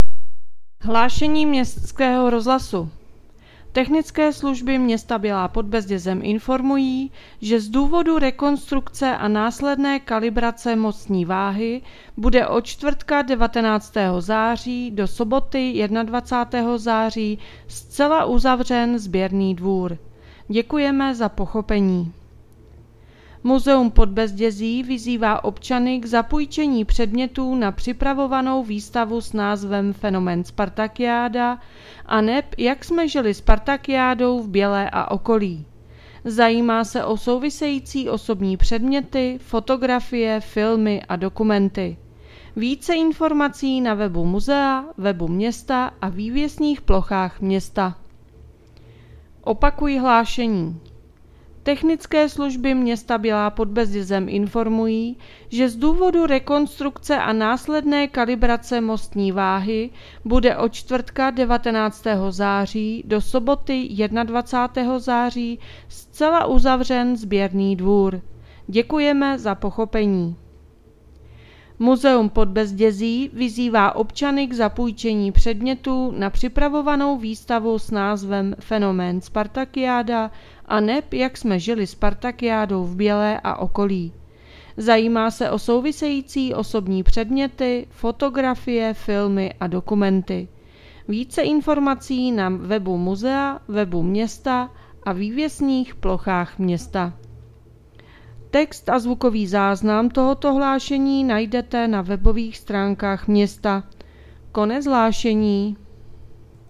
Hlášení městského rozhlasu 18.9.2024